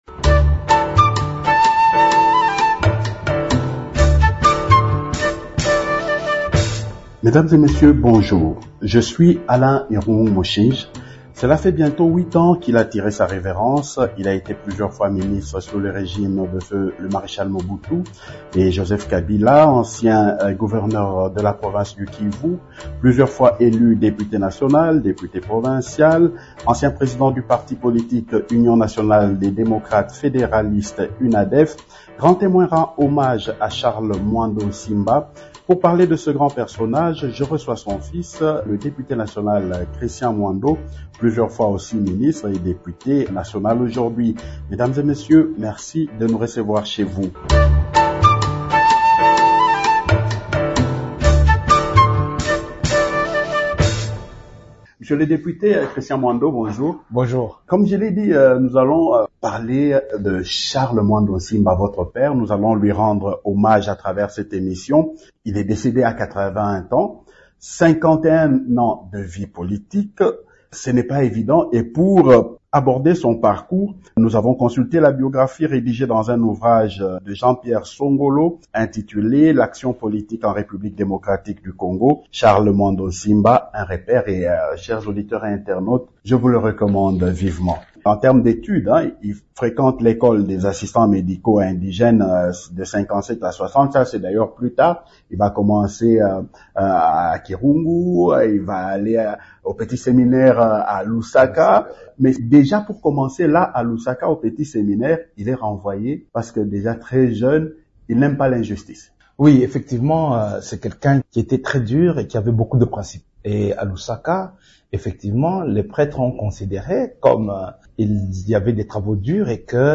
Pour Christian Mwando qui a accordé cette interview à Radio Okapi, Charles Mwando était un fin négociateur et un politicien conciliant.